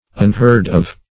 Unheard-of \Un*heard"-of\, a.